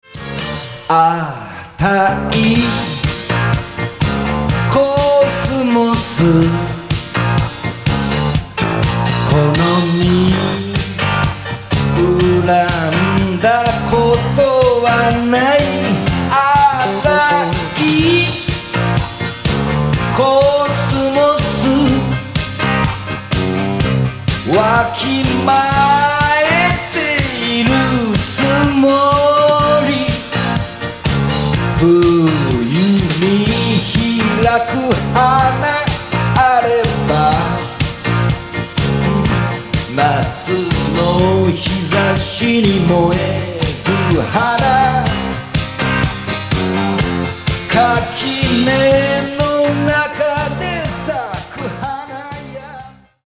ジャンル：フォーク/ロック